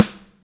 damped snare p.wav